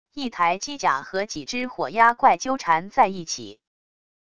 一台机甲和几只火鸦怪纠缠在一起wav音频